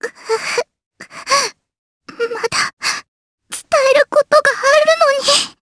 Rehartna-Vox_Dead_jp.wav